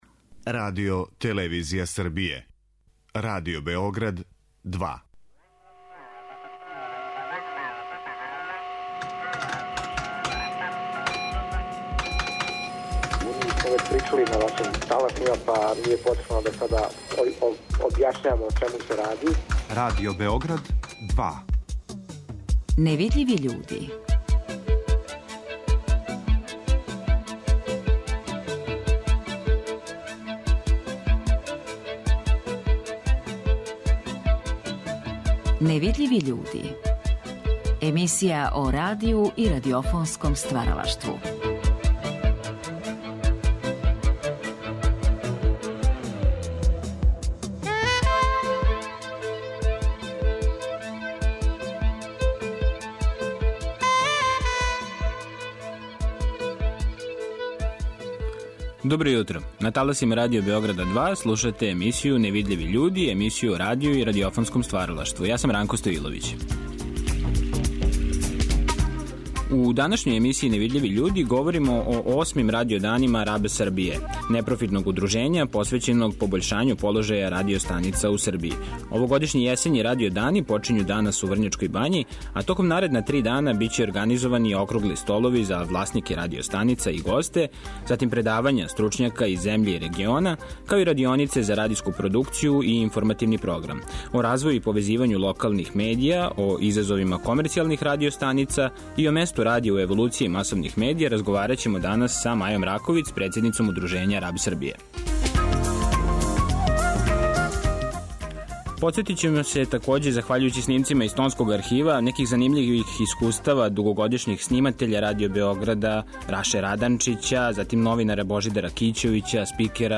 Емисија о радију и радиофонском стваралаштву.